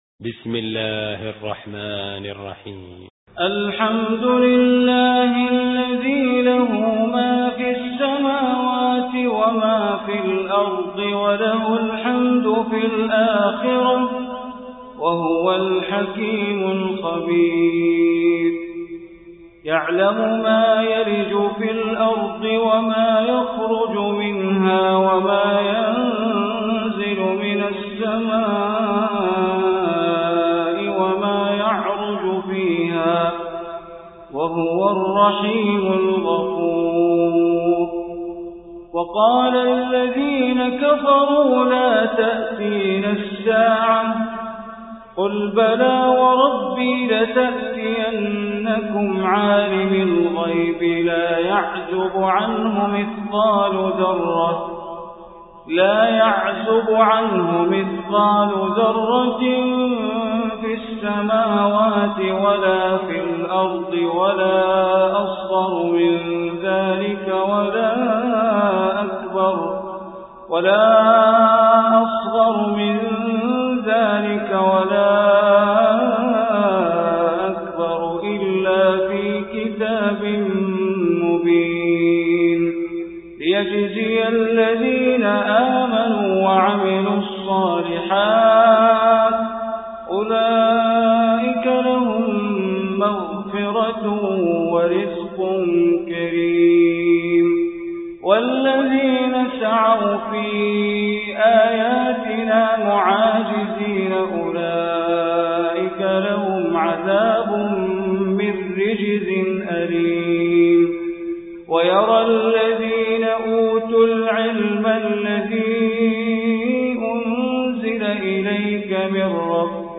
Surah Saba Recitation by Sheikh Bandar Baleela
Surah Saba, listen online mp3 tilawat / recitation in Arabic in the voice of Imam e Kaaba Sheikh Bandar Baleela.